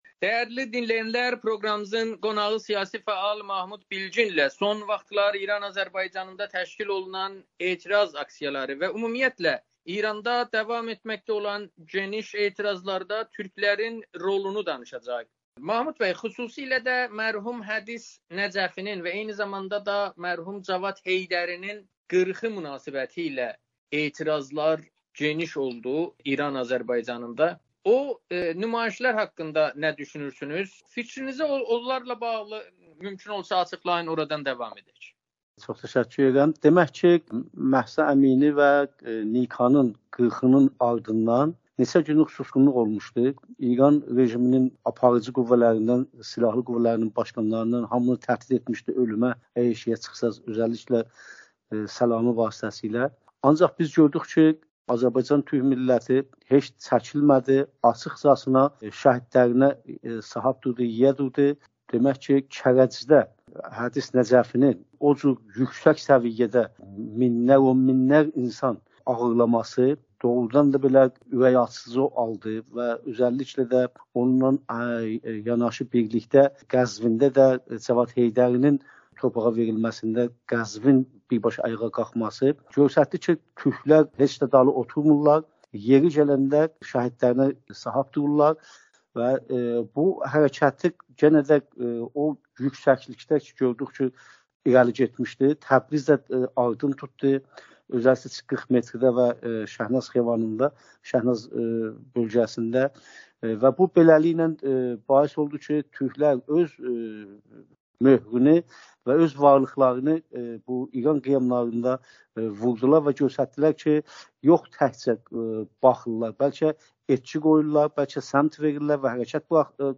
Amerikanın Səsi ilə söhbətdə İran Azərbaycanında təşkil olunan etiraz aksiyalarında səsləndirilən şüarları şərh edib.